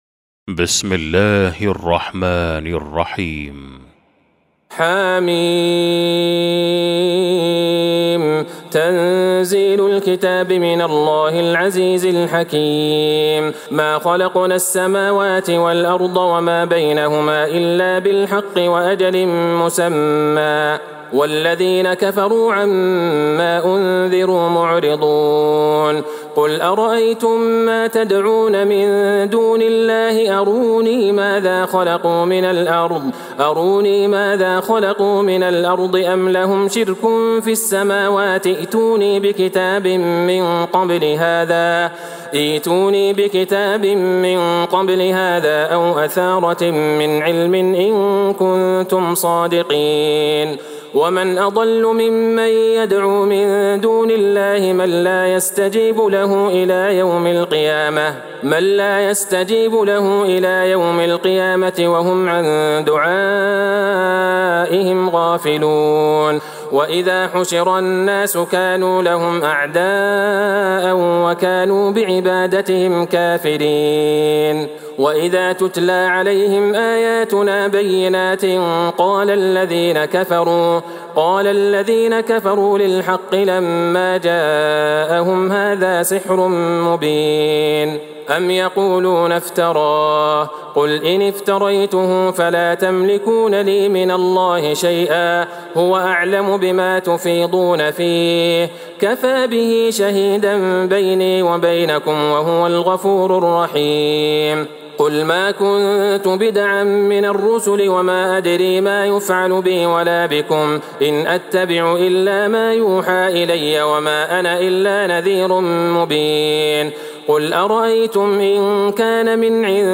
سورة الأحقاف Surat Al-Ahqaf > مصحف تراويح الحرم النبوي عام 1443هـ > المصحف - تلاوات الحرمين